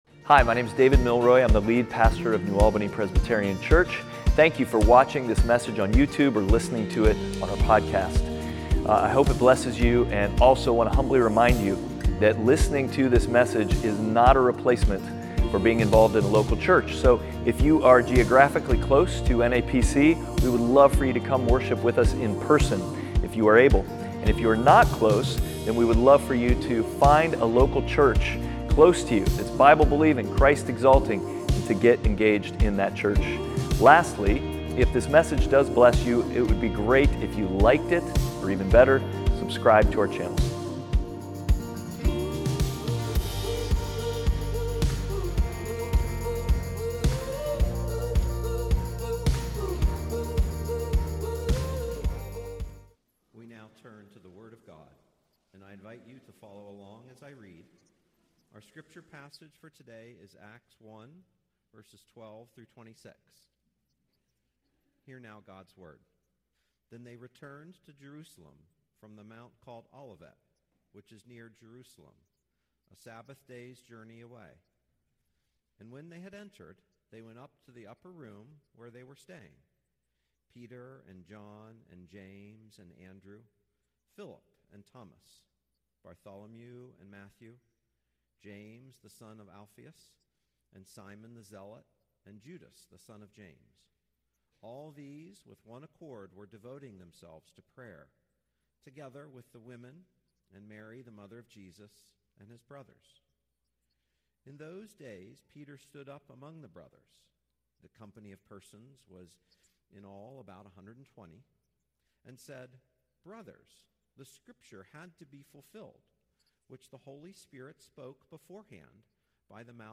NAPC_Sermon9.14.25.mp3